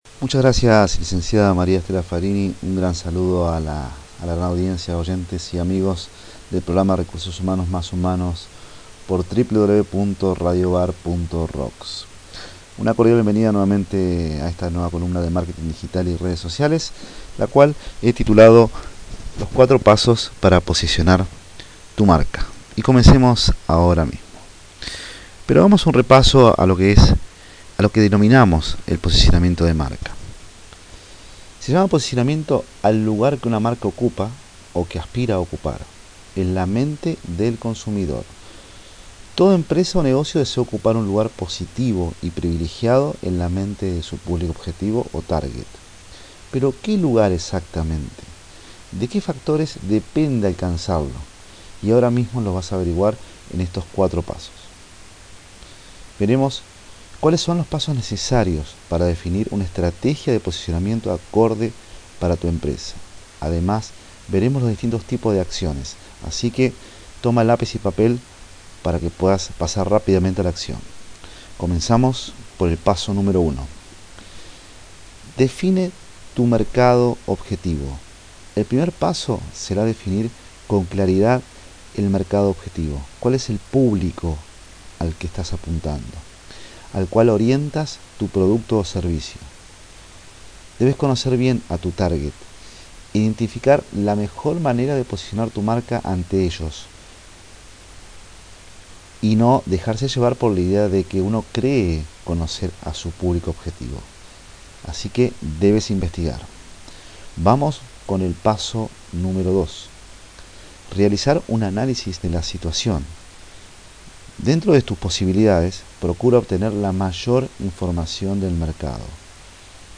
En esta nueva entrada me gustaría compartir contigo, la ultima Columna Radial Grabada donde podrás descubrir los » 4 Pasos para Posicionar Tu Marca «, de manera sencilla y con ejemplos prácticos, conocer aspectos claves para que Tu Marca Posicione y establecer una relación duradera con Tus Potenciales Clientes.
Espero esta nueva grabación Audio-Entrevista de la columna radial de Marketing Digital y Redes Sociales sobre: » 4 Pasos para Posicionar Tu Marca » sea de utilidad y puedas poner rápidamente en práctica.